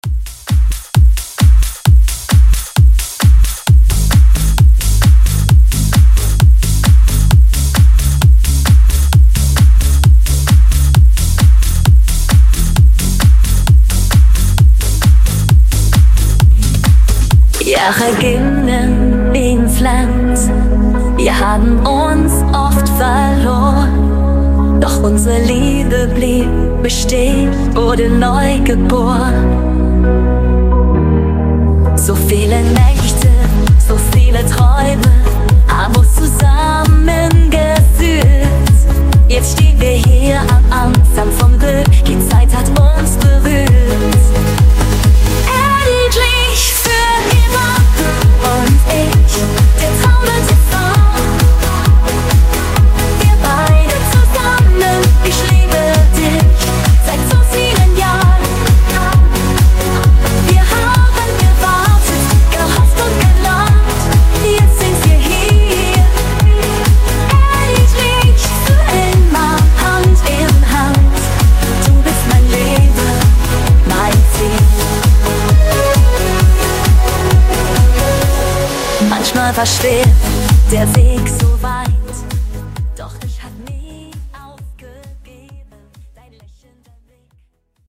Genre: GERMAN MUSIC Version: Clean BPM: 92 Time